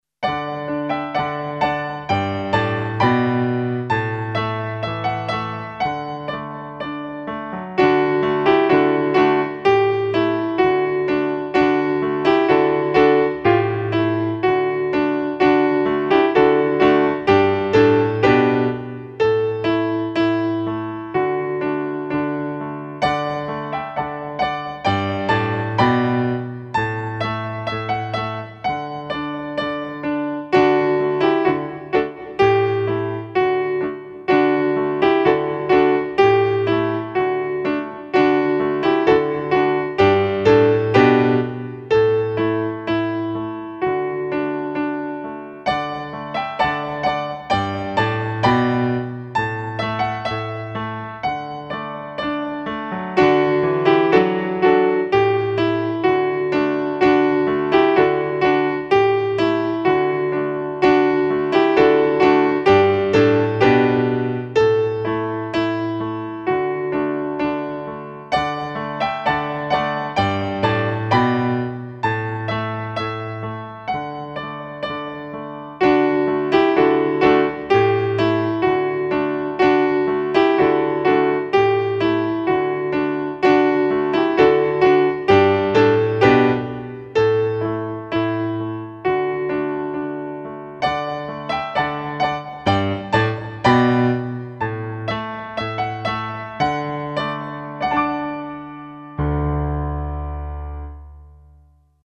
Akompaniament fortepianowy